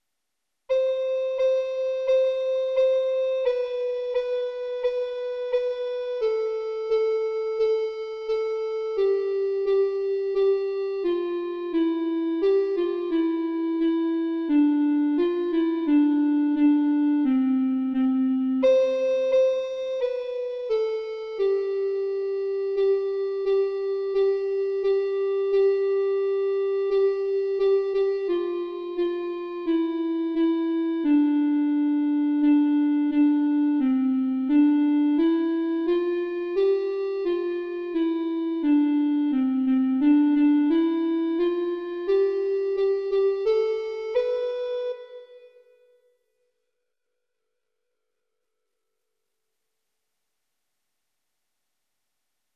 Canon voor 4 stemmen
Een Zweeds lied
eenstemmig